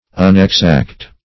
Meaning of unexact. unexact synonyms, pronunciation, spelling and more from Free Dictionary.